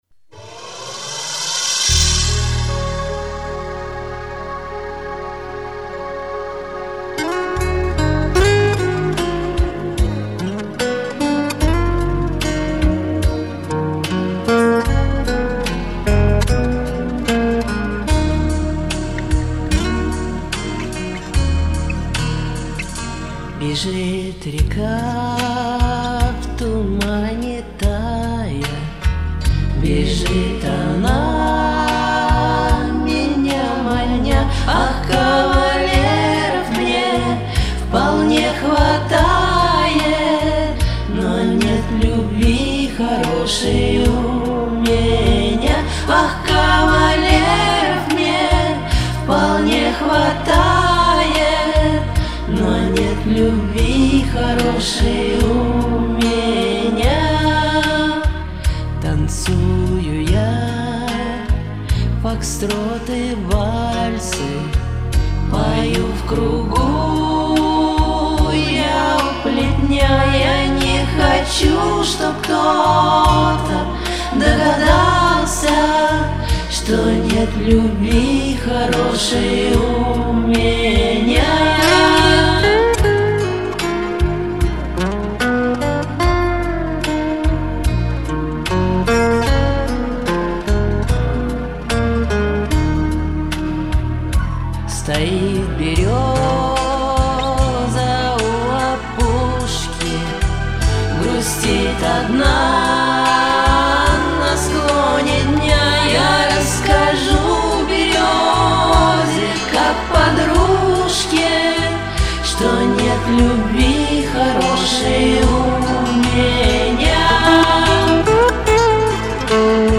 здесь я, и голоса все мои)
Сама люблю пропевать в песнях всякие подголосочки...